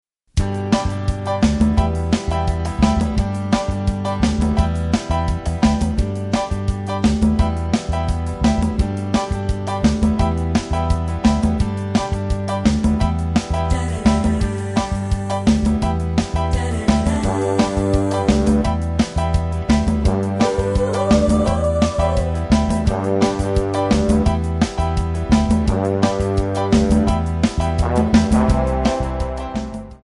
MPEG 1 Layer 3 (Stereo)
Backing track Karaoke
Pop, Oldies, 1960s